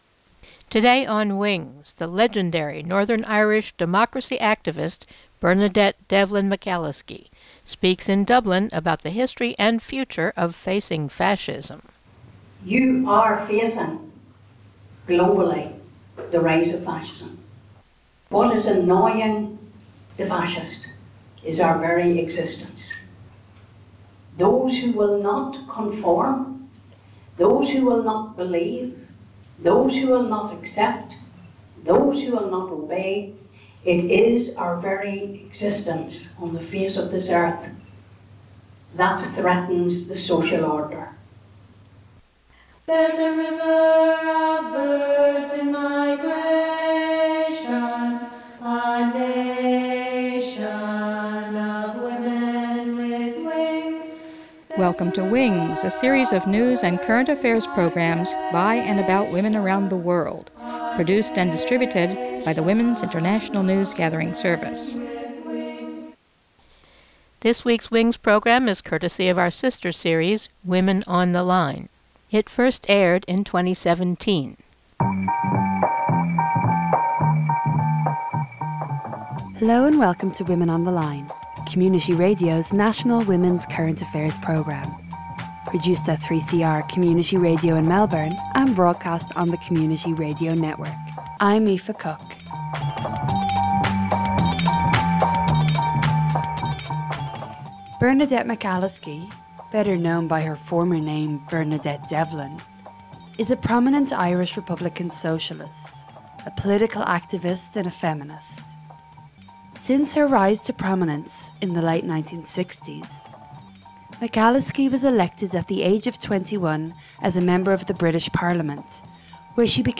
She gave this passionate speech in Dublin in 2017, upon the 80th anniversary of the bombing of Guernica in 1937 in the Basque region of Spain. She speaks of the increase of fascism, of fascism as de-humanization, Muslims as the new scapegoats, the political origins and philosophies of republicanism vs nationalism, and populist fascist Donald Trump.